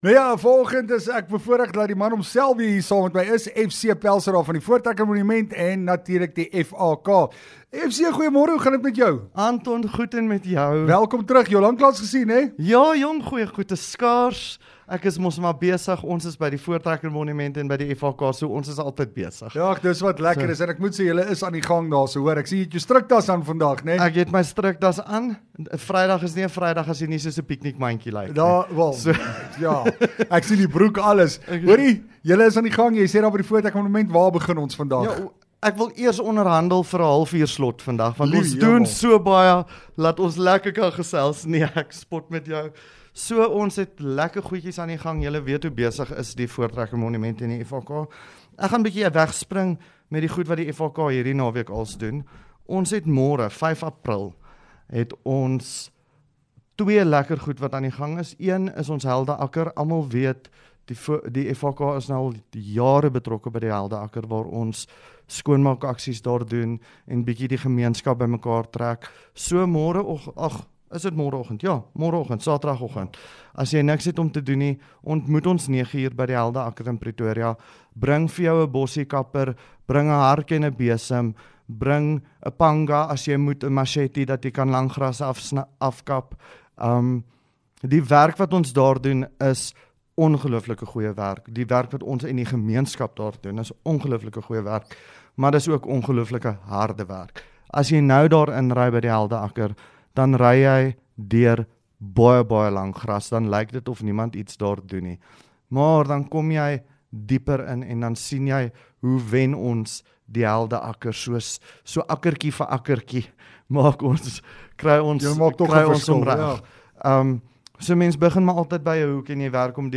LEKKER FM | Onderhoude 4 Apr Voortrekker Monument